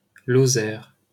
Lozère (French pronunciation: [lɔzɛʁ]